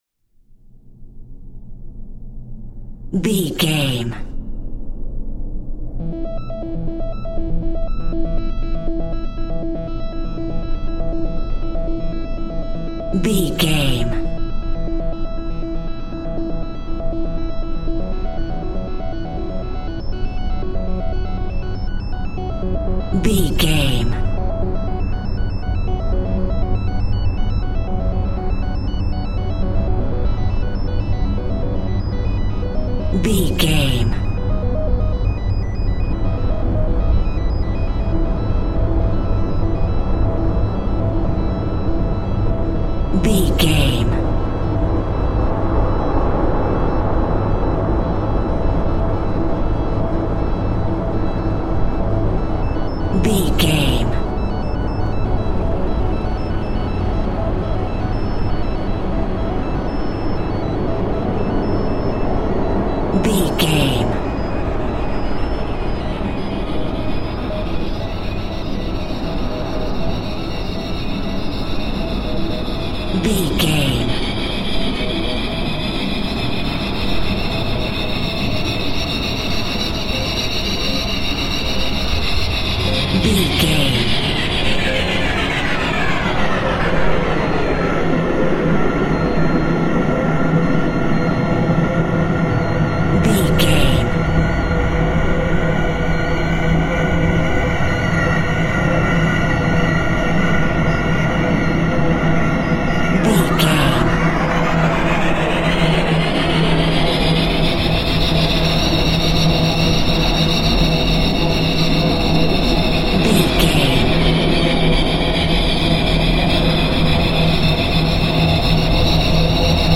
In-crescendo
Aeolian/Minor
Slow
scary
tension
ominous
dark
suspense
eerie
synthesiser
horror
keyboards
ambience
pads
eletronic